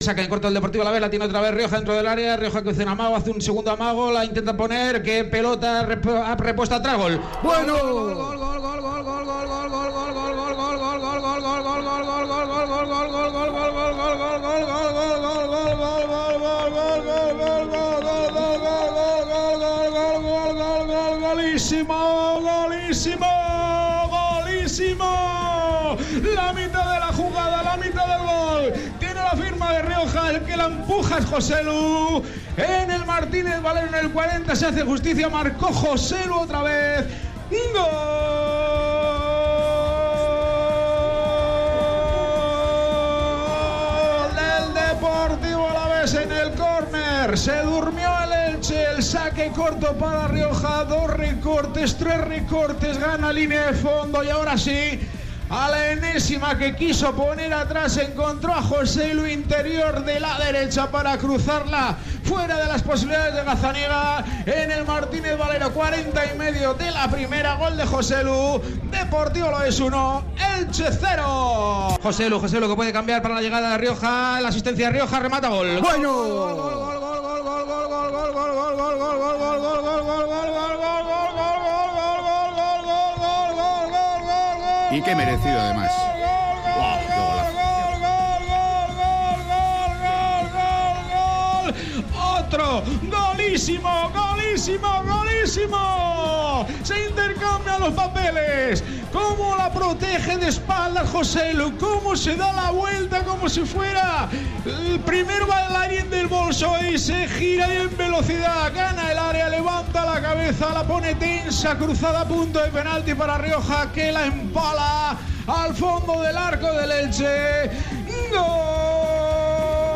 Aquí puedes volver a escuchar la narración de los goles de Joselu y Rioja